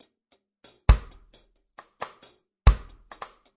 雷鬼鼓循环1
描述：鼓循环
标签： 78 bpm Reggae Loops Drum Loops 4.14 MB wav Key : Unknown
声道立体声